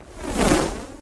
throw_jack_o_lantern.ogg